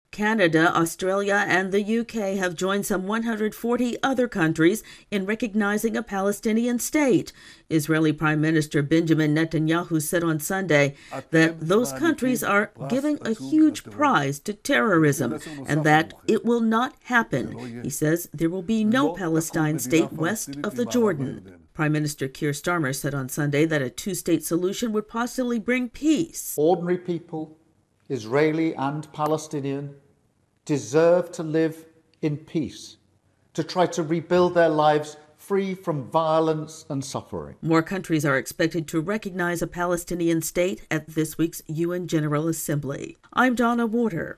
More countries recognizing a Palestinian state has drawn angry response from Israel. AP correspondent